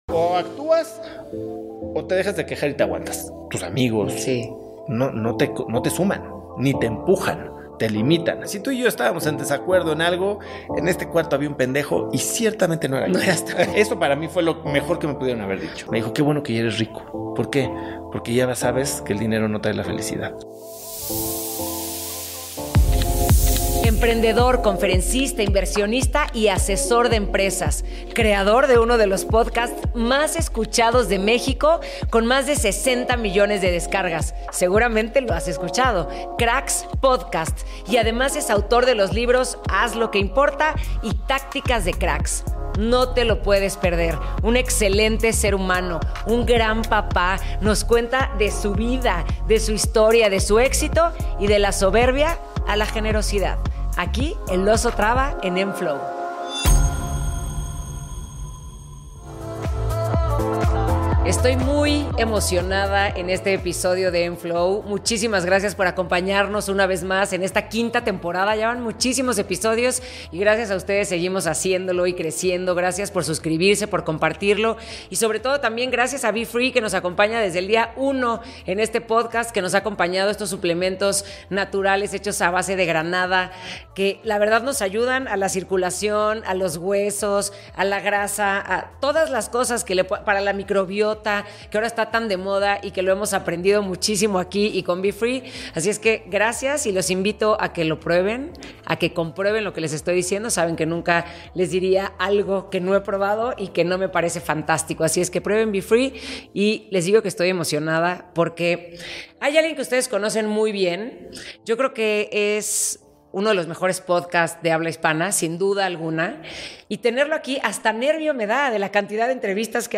Get ready for an honest, direct conversation full of insights that can change the way you view success.